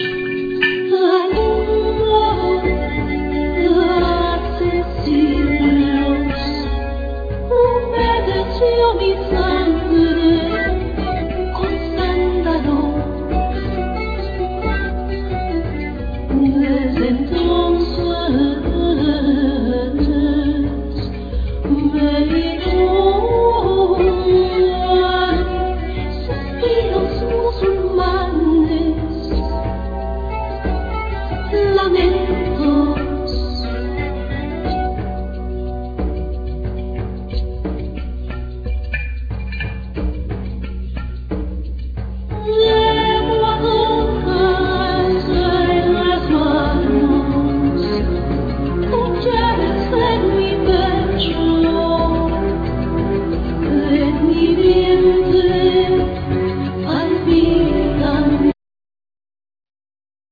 Voices
Guitars,Bouzouki,Dulcimer,Voices
Clarinet,Bass Clarinet,Saxophne
Piano